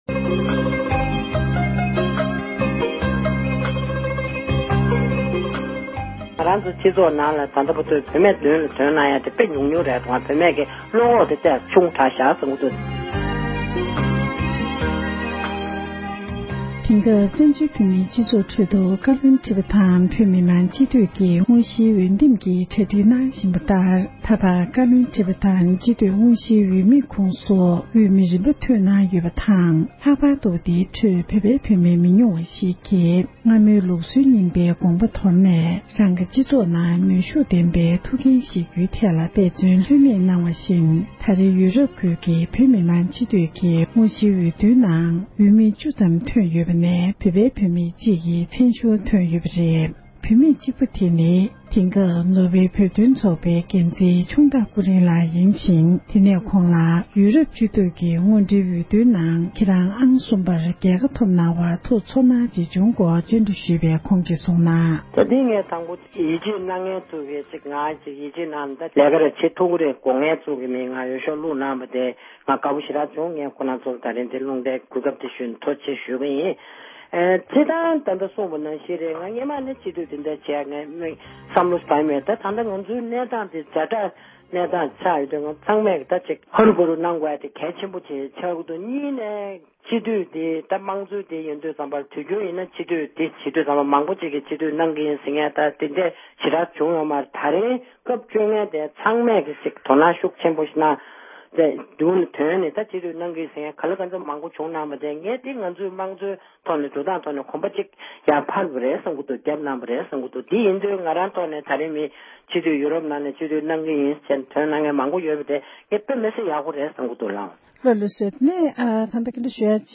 འབྲེལ་ཡོད་མི་སྣར་བཀའ་འདྲི་ཞུས་པར་གསན་རོགས༎